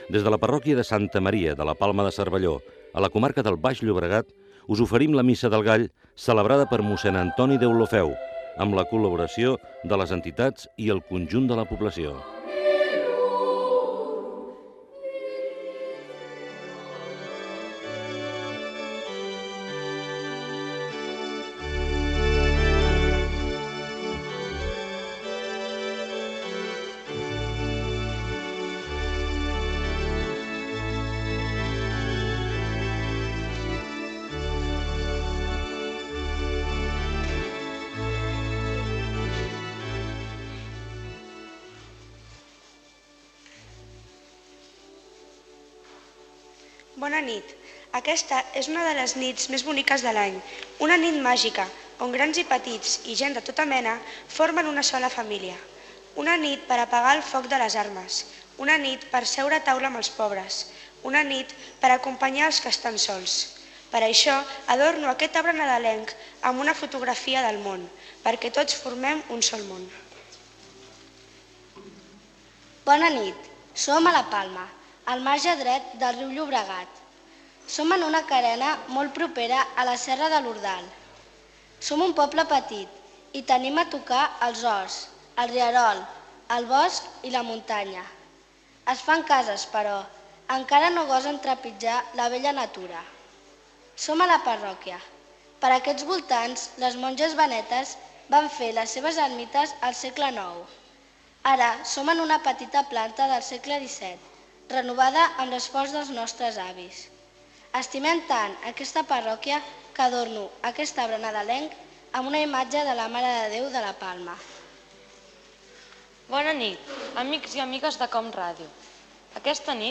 Inici de la transmissió de la Missa del gall des de la parròquia de Santa Maria de La Palma de Cervelló.